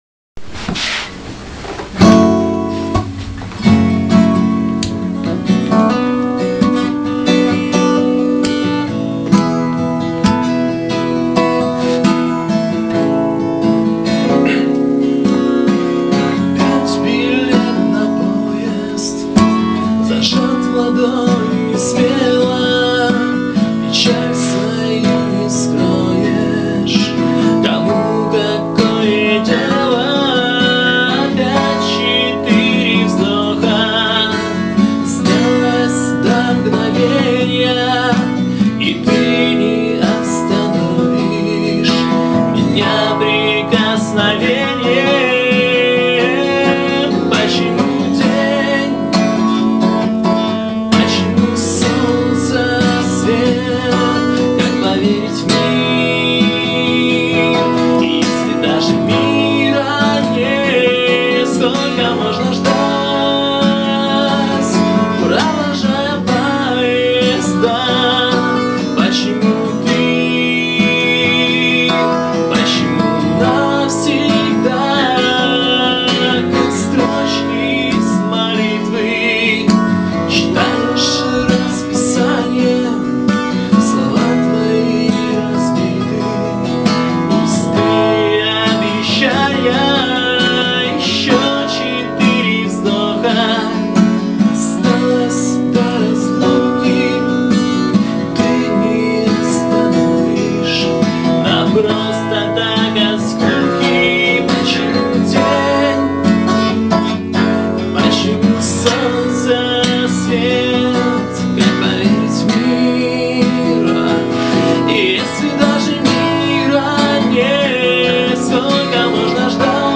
под гитару